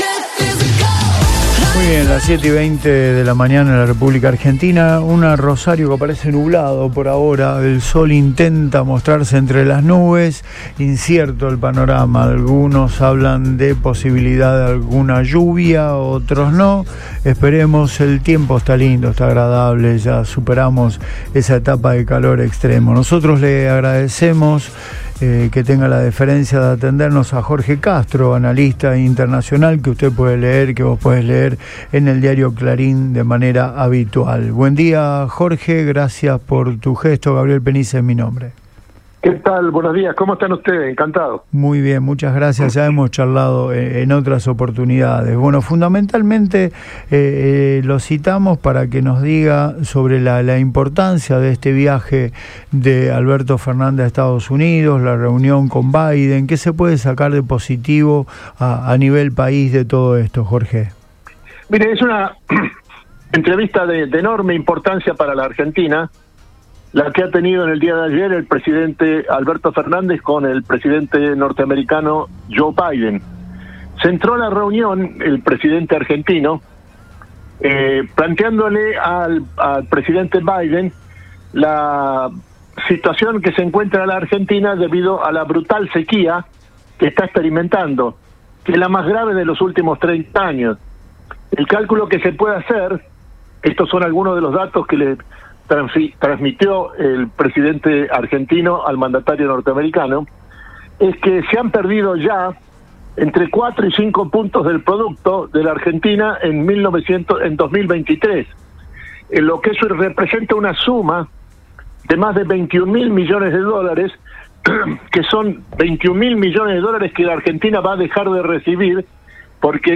por Radio Boing